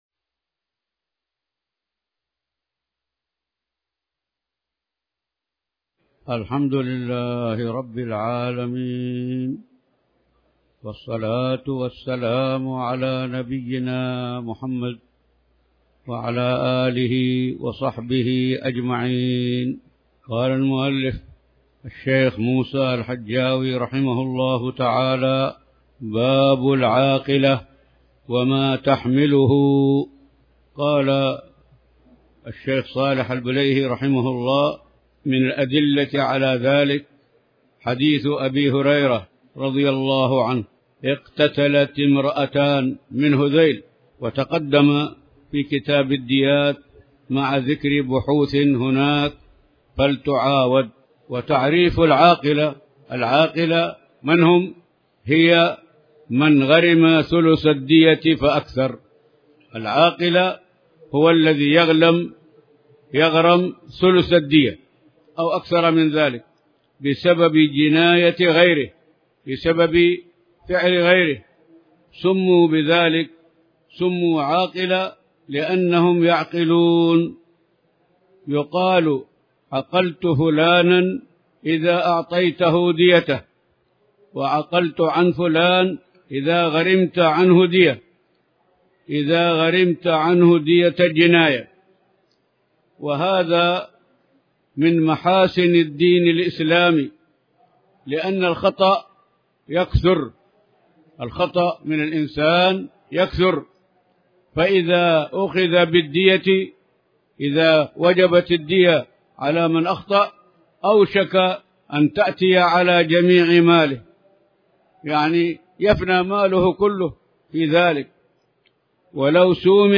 تاريخ النشر ١٧ ذو الحجة ١٤٣٩ هـ المكان: المسجد الحرام الشيخ